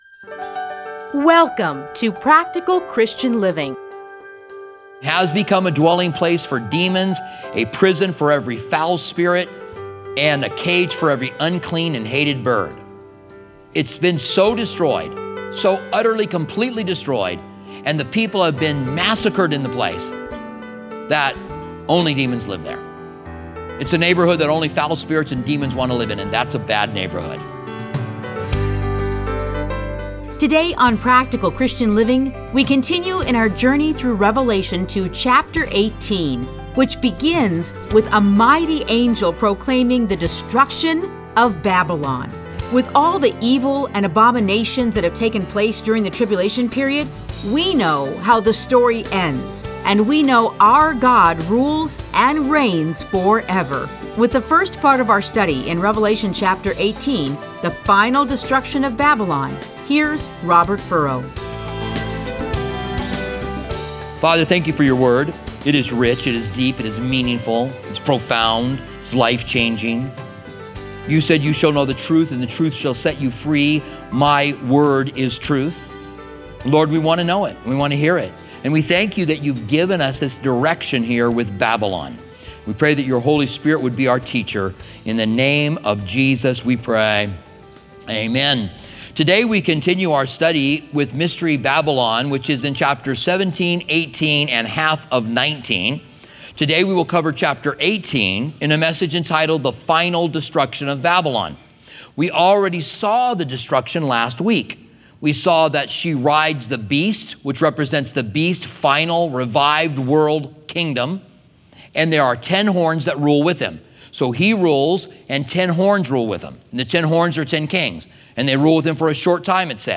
Listen to a teaching from Revelation 18:1-24.